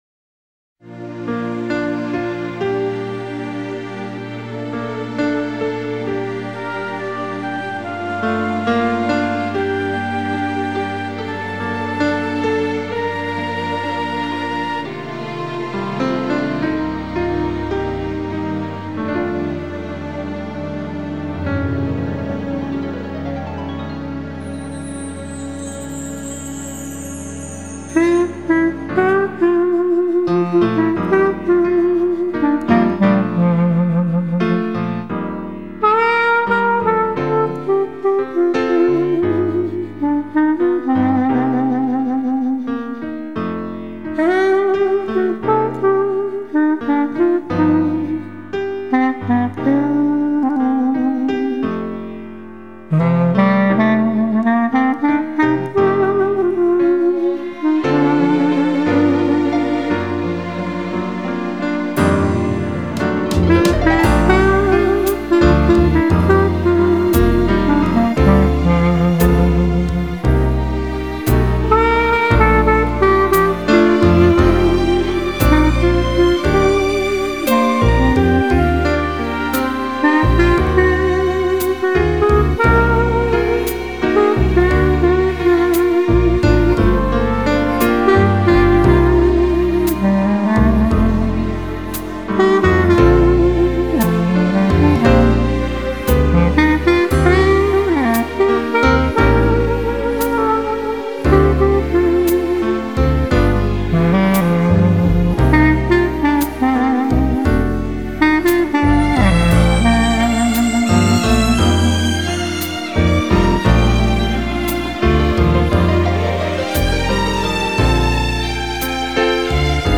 风格流派: Pop
好莱坞周年天碟——黑管大师吹奏奥斯卡电影，演录具佳之天碟.